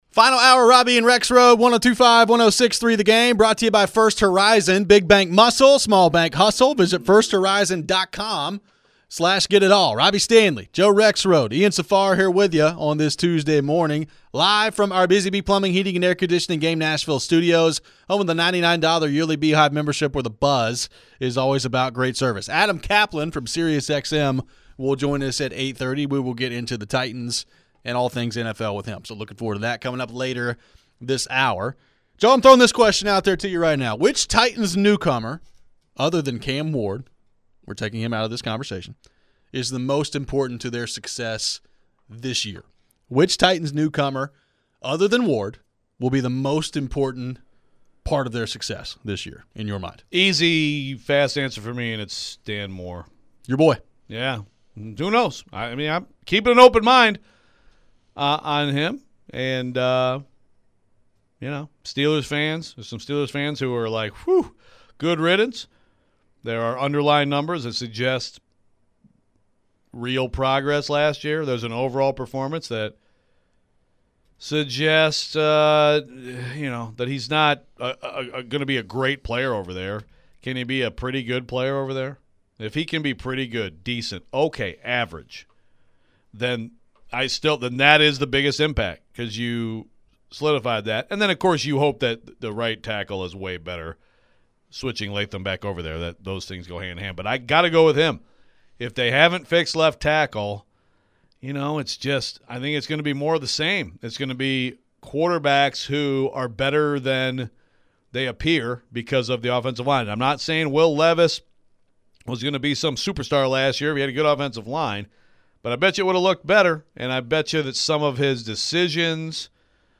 We get back into the Titans and ask the question, other than Cam Ward, who is the most important player for the team this season? How worried are we about pass rush and can the OL improve enough? We head to the phones.